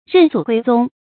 认祖归宗 rèn zǔ guī zōng
认祖归宗发音